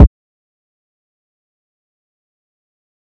Kick (6).wav